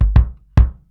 Index of /90_sSampleCDs/E-MU Producer Series Vol. 8 – Platinum Phatt (CD 1)/PlatinumPhattCD1/Live Kicks